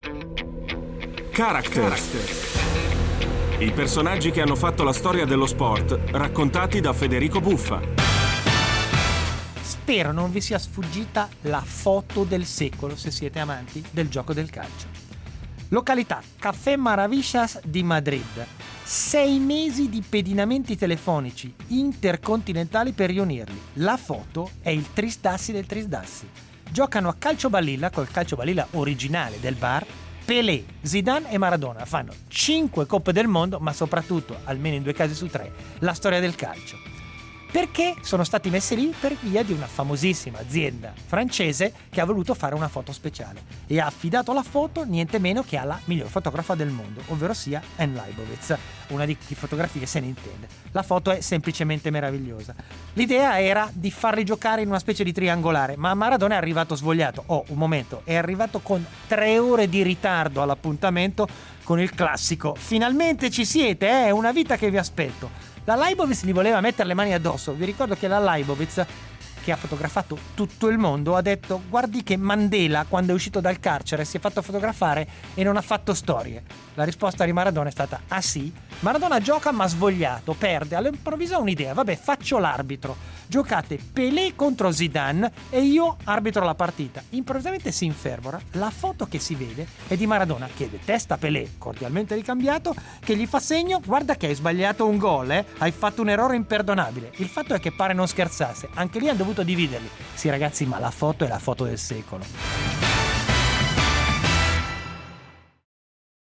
La storica fotografia della partita a calcio balilla tra Pelé, Maradona e Zidane, copertina della campagna pubblicitaria di Louis Vuitton nel 2010, raccontata da Federico Buffa.